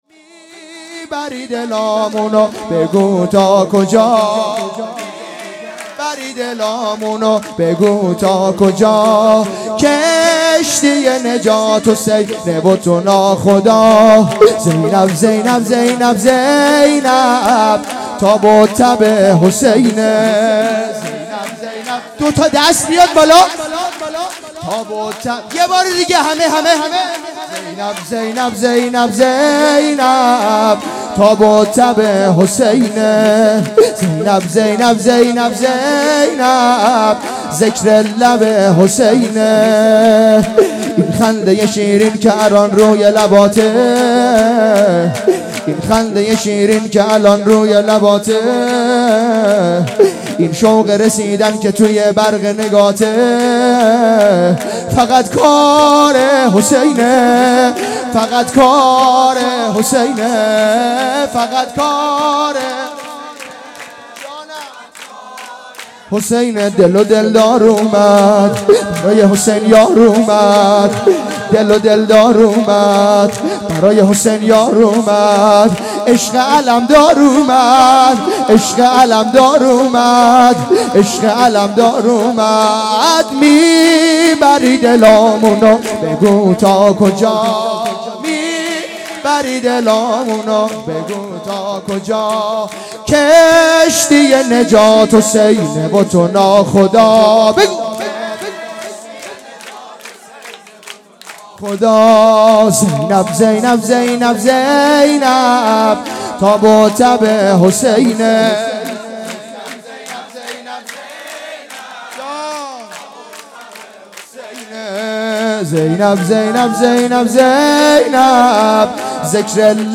جشن ولادت حضرت زینب سلام الله علیها